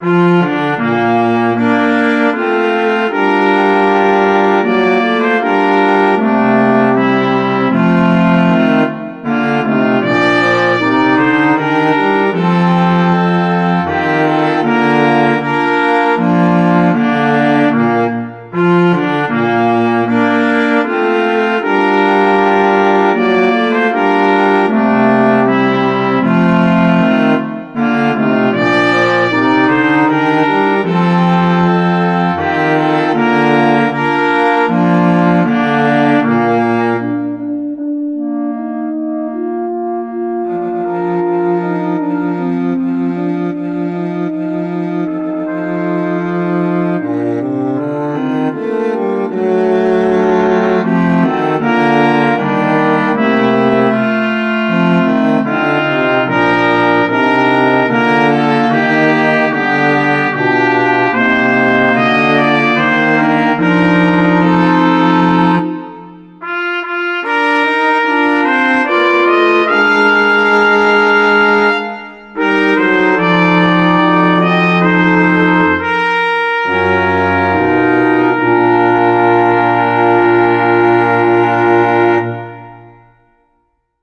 Voicing: Flexible 4